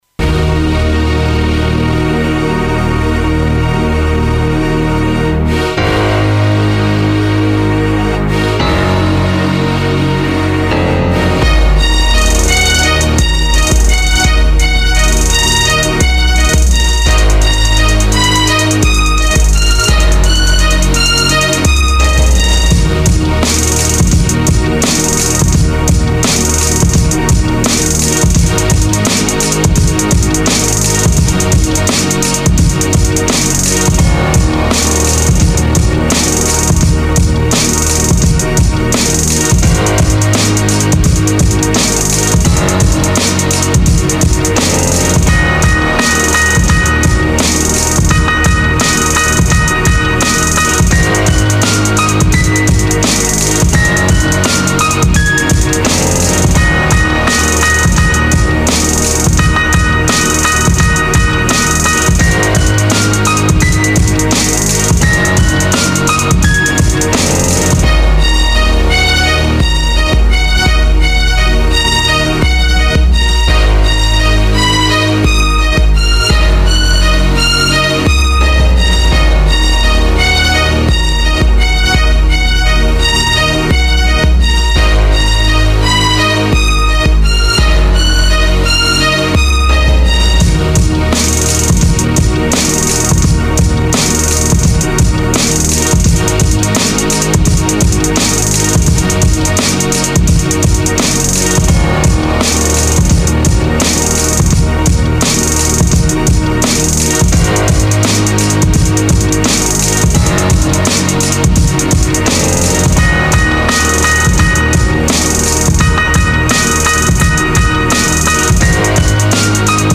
Beats